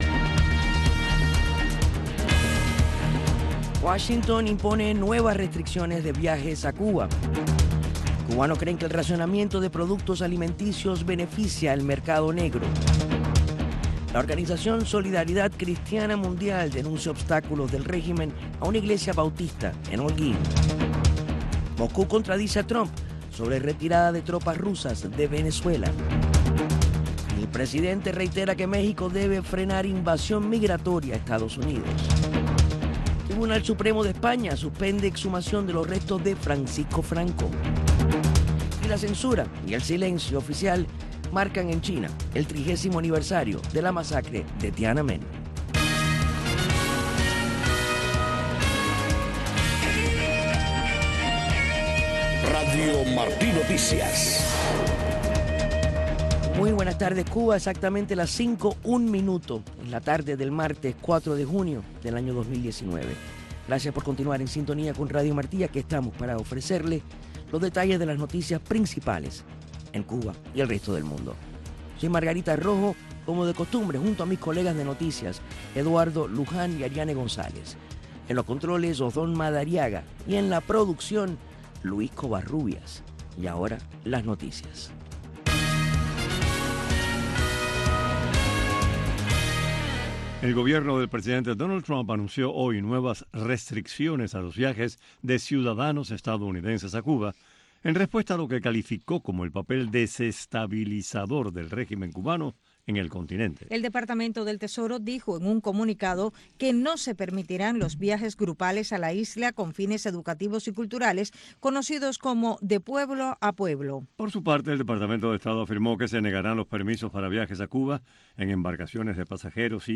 Noticiero de Radio Martí 5:00 PM